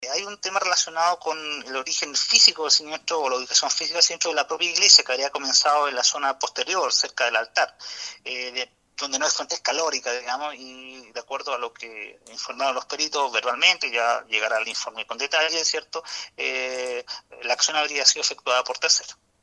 Otras indagatorias de la PDI permitió fijar el lugar en el que habría comenzado el incendio, siendo la parte posterior del templo, donde esta persona habría originado el fuego, indicó el fiscal.